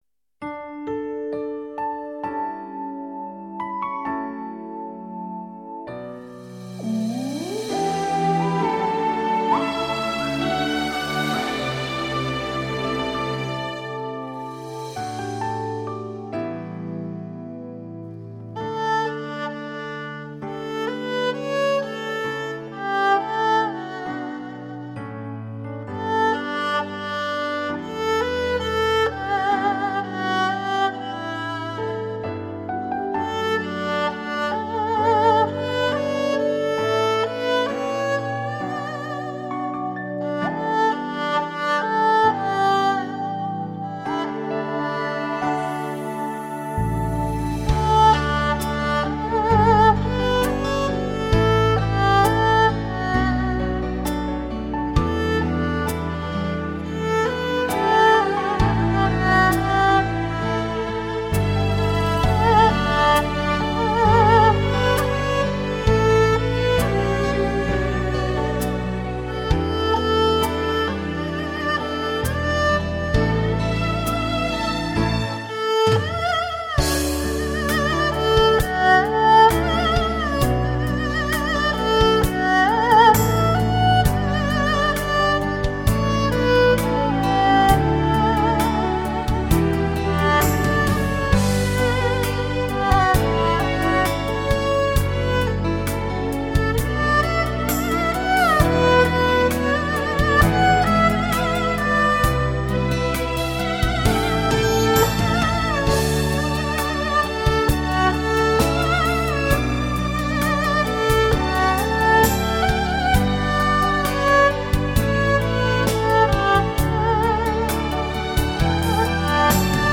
别样的二胡乐声中，回忆是山温水暖，离别是哀而不伤，牵挂是风情缱绻，爱情是浅浅清清。